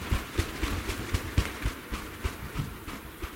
近战战斗的声音 " Woosh 10
描述：冲床
标签： swosh swhish 冲床 近战手 woosh 打嗖
声道立体声